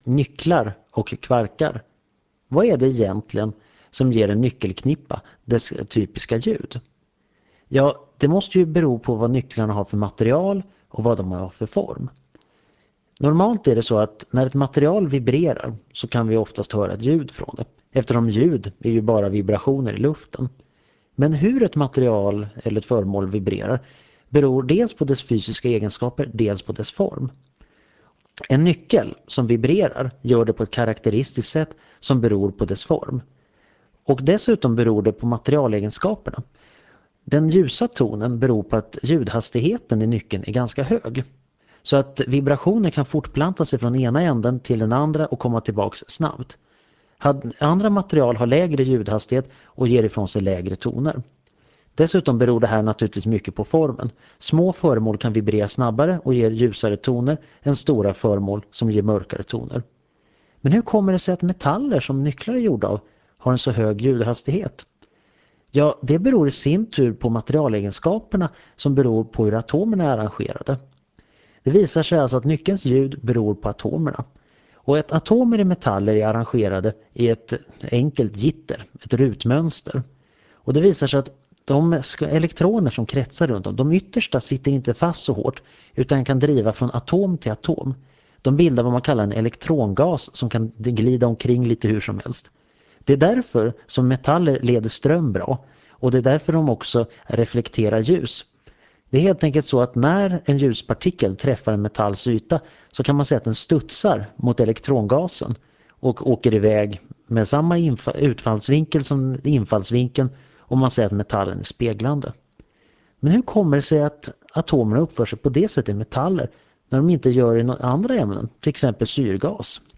Föredraget